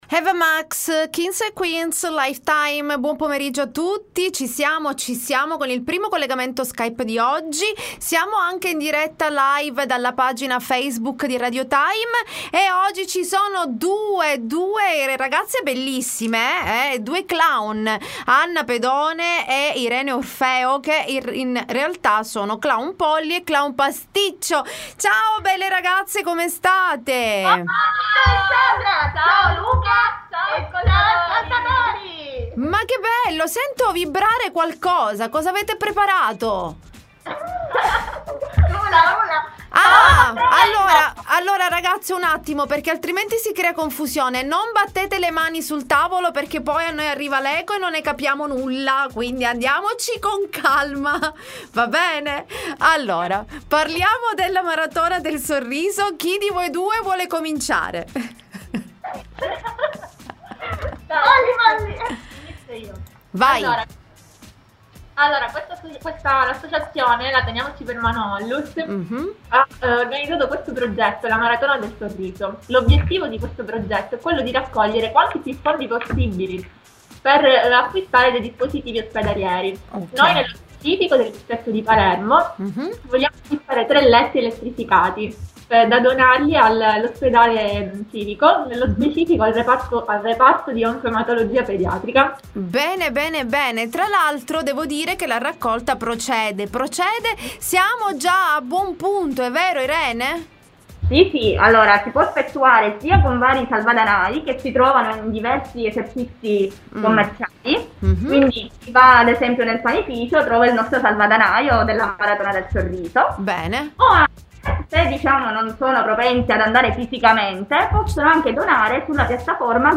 L.T. Intervista Teniamoci Per Mano Onlus